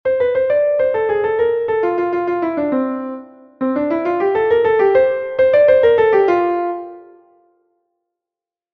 Vivacissimo.1.mp3